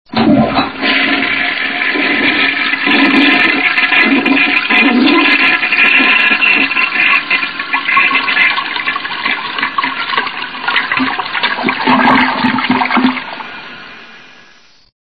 File Name: Washbasin.mp3